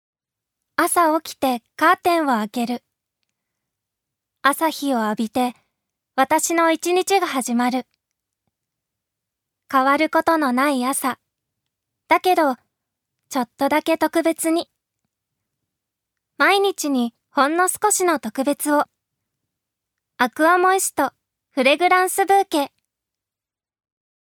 ジュニア：女性
ナレーション３